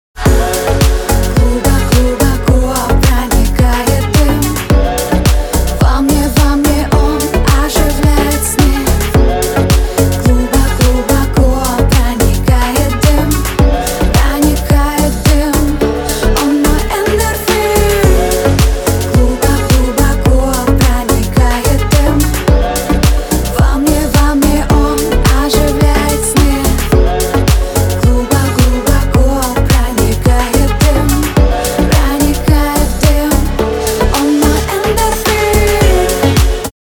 • Качество: 320, Stereo
поп
громкие
deep house
чувственные